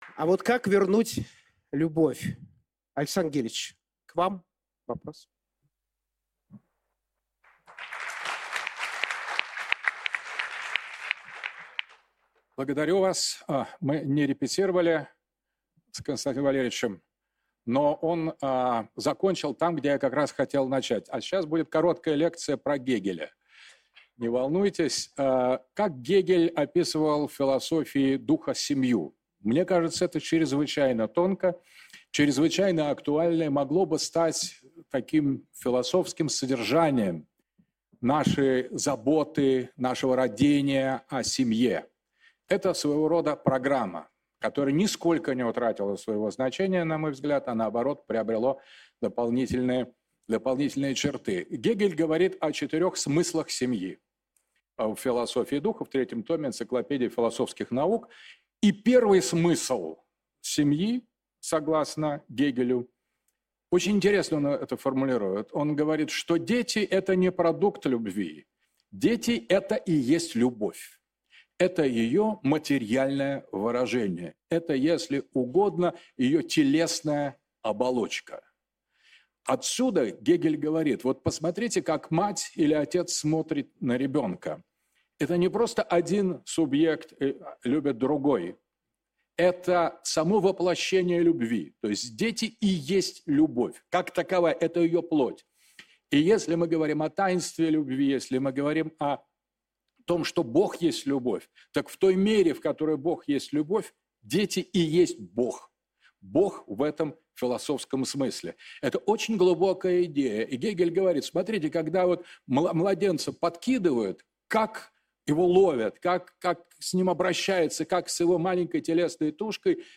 Выступление Александра Дугина на форуме «Россия-2050: Образ будущего, Семья на первом месте»